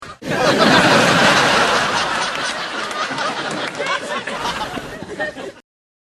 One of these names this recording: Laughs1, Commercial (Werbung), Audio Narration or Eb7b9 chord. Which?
Laughs1